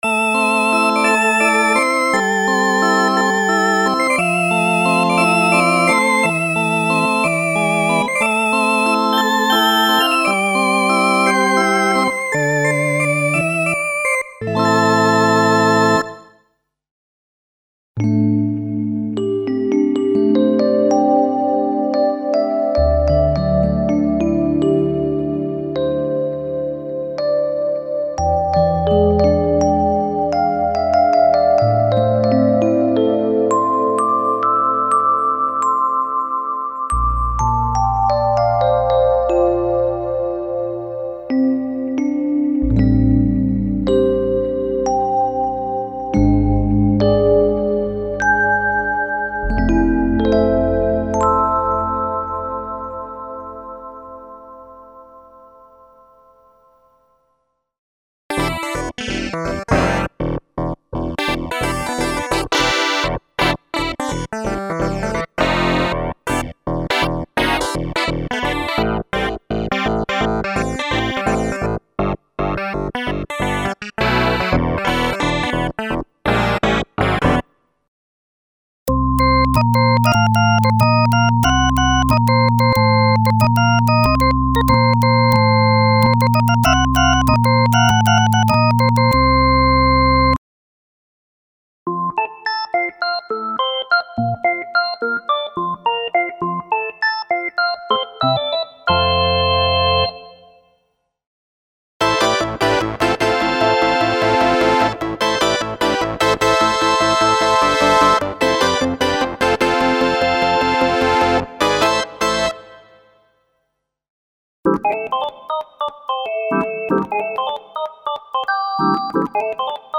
A complete collection of piano and organ sound programs in traditional or less conventional retro feeling, including a complete KB 3 Mode of drawbar organ emulations.